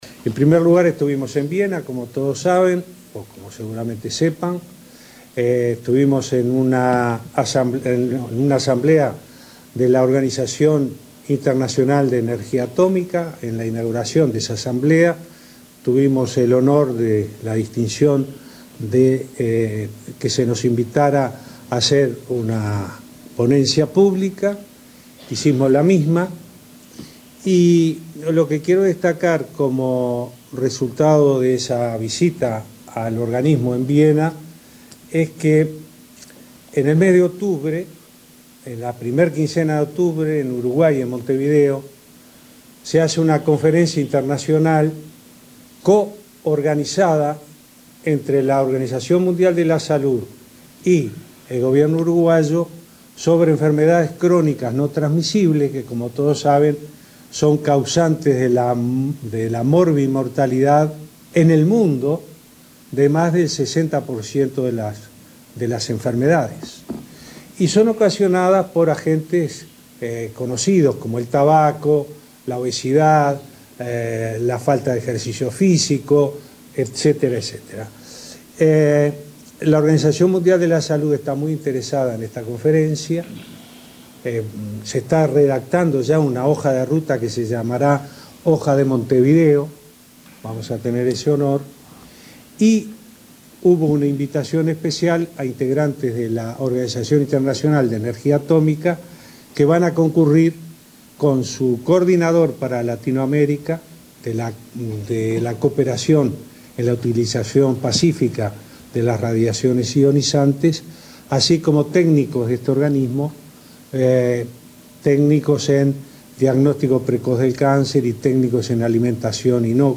El presidente Tabaré Vázquez hizo un repaso de su gira por Austria, Egipto y Suiza. Destacó su participación en la asamblea de la Organización Internacional de Energía Atómica y recordó que en octubre se realizará en Montevideo una conferencia organizada por la OMS y Uruguay sobre enfermedades crónicas no transmisibles. En Egipto confirmó el incremento de comercio de carne y en Ginebra realizó su ponencia ante la OIT.